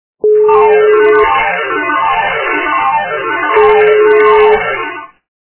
При прослушивании Игровой автомат - Джек пот качество понижено и присутствуют гудки.
Звук Игровой автомат - Джек пот